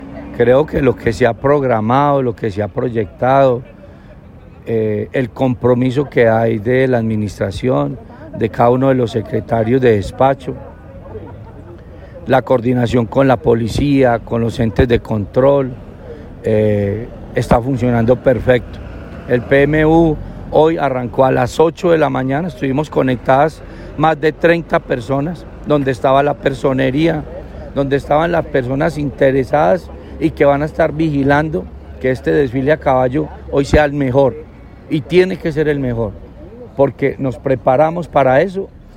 Escuchar Audio: Alcalde Roberto Jiménez Naranjo.